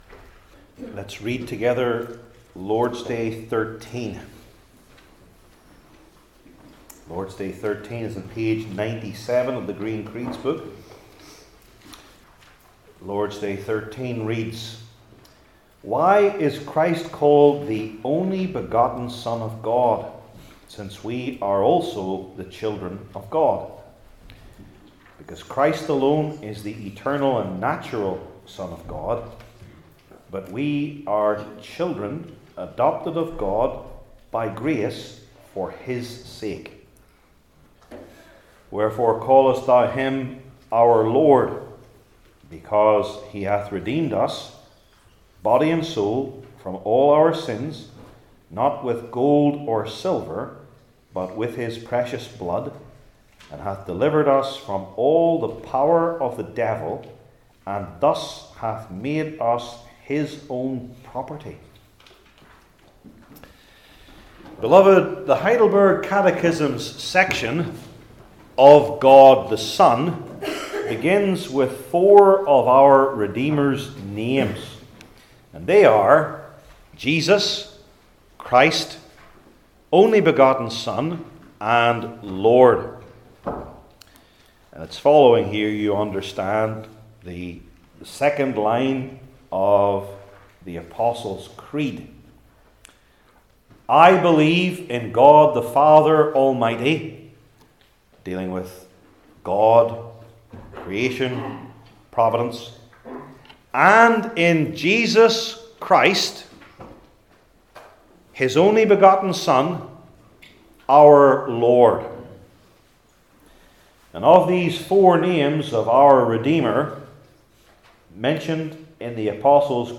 Heidelberg Catechism Sermons I. The Calling II.